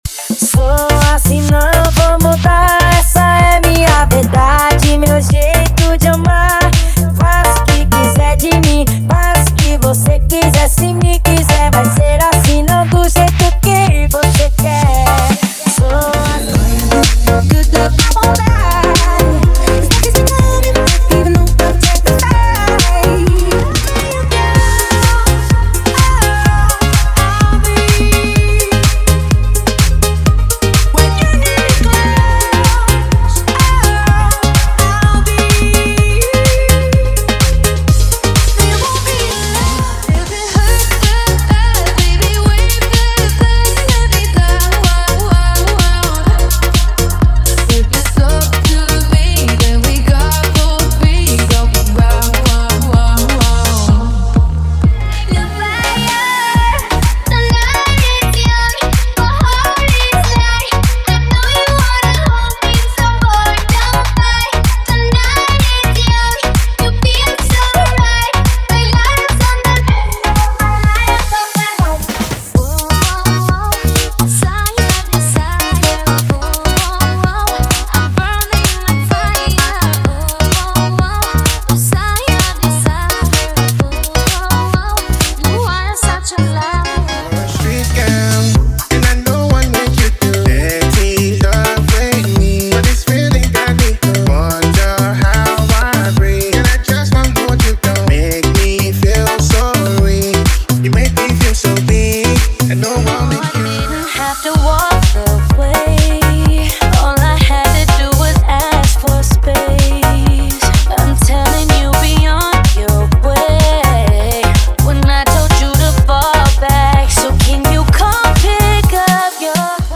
• Dance Comercial = 75 Músicas